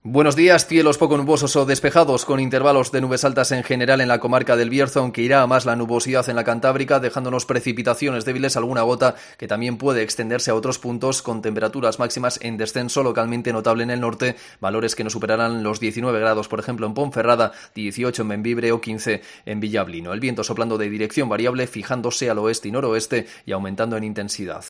Previsión del tiempo